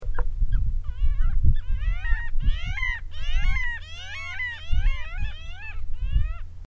parasitic jaeger
Stercorarius parasiticus